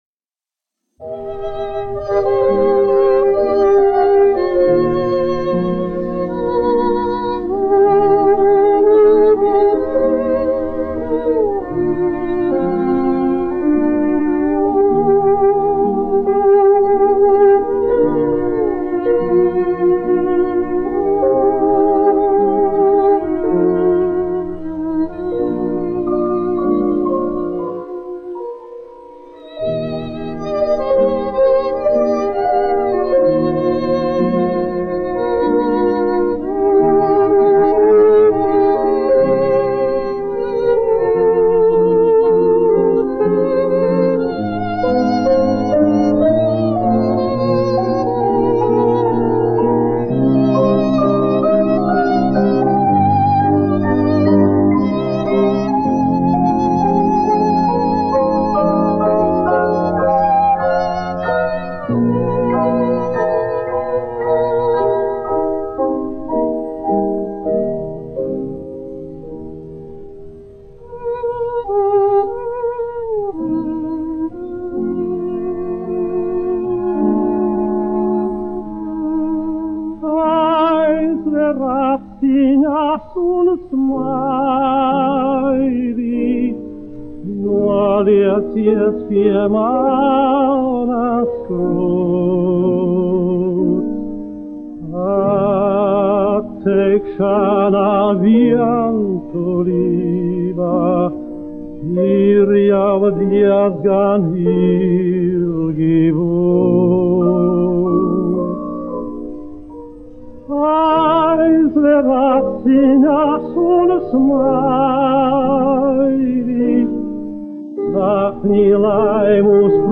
1 skpl. : analogs, 78 apgr/min, mono ; 25 cm
Dziesmas (augsta balss) ar instrumentālu ansambli
20. gs. 30. gadu oriģinālās skaņuplates pārizdevums ASV
Latvijas vēsturiskie šellaka skaņuplašu ieraksti (Kolekcija)